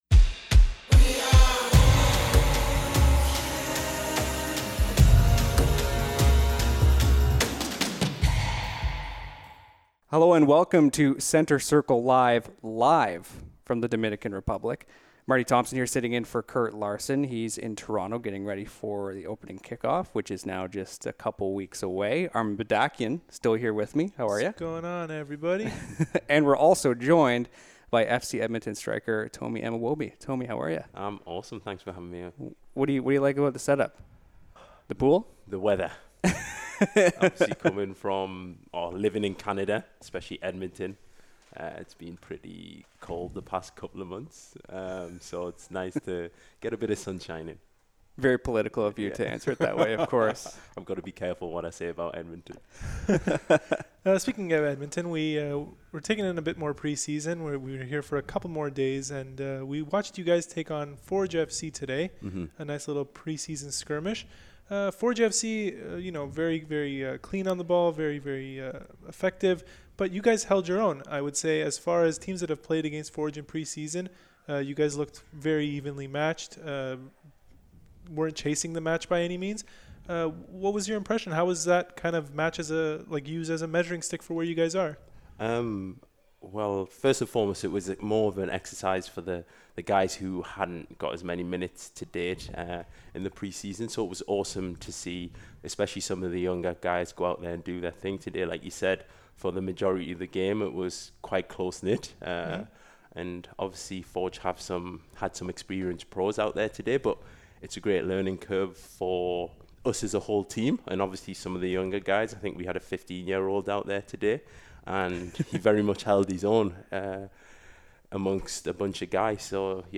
interviews from the Dominican Republic